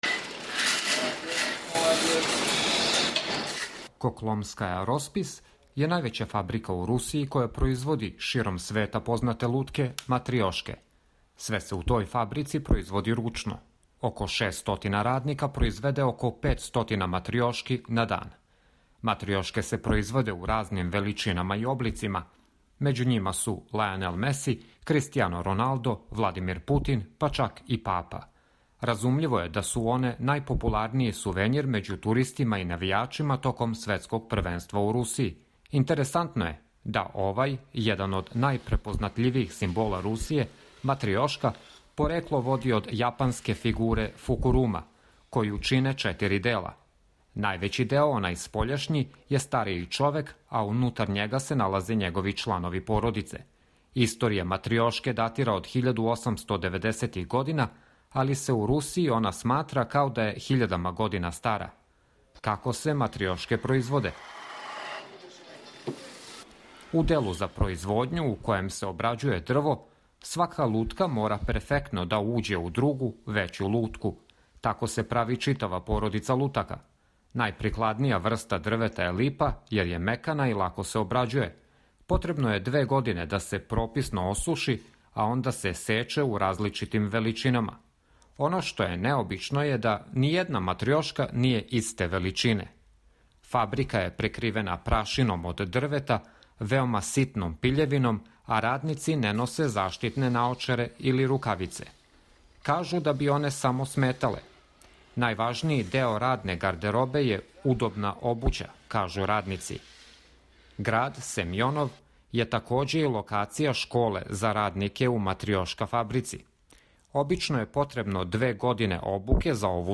Сазнајте у репортажи из највеће руске фабрике матрёшки.